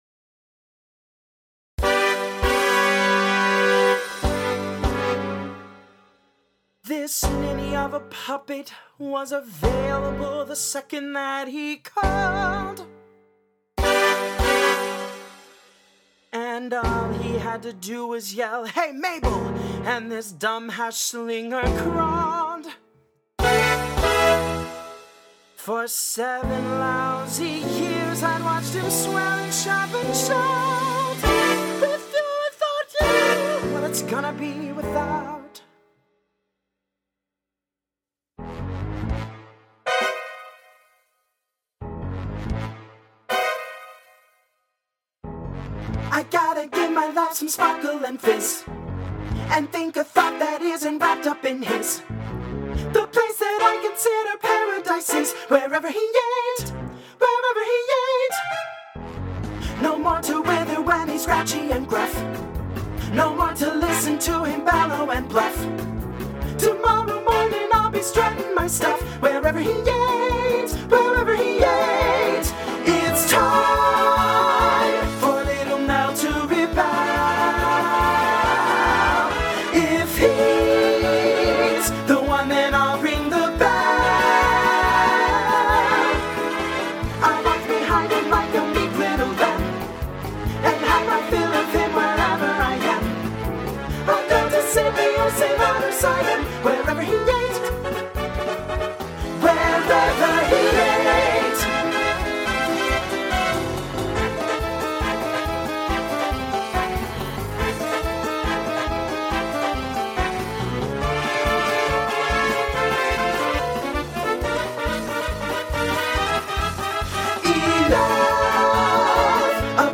Voicing SSA Instrumental combo Genre Broadway/Film
Mid-tempo